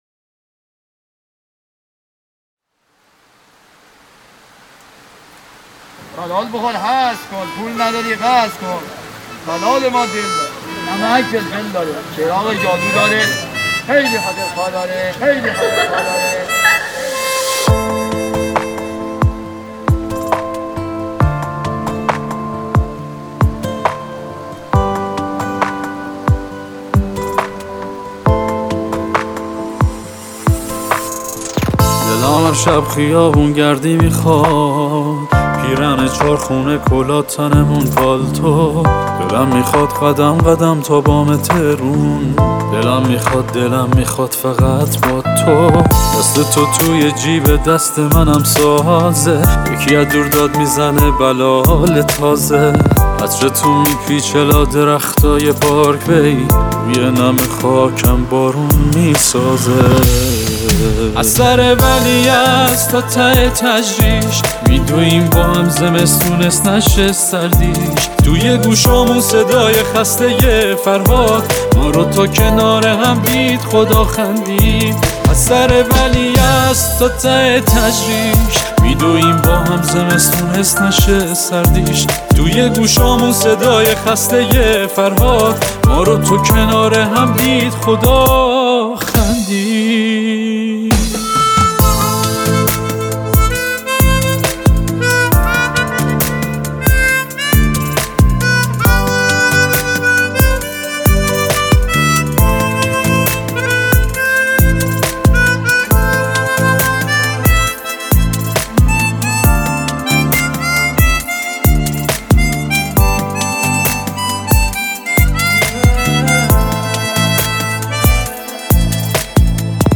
بک وکال
ساز دهنی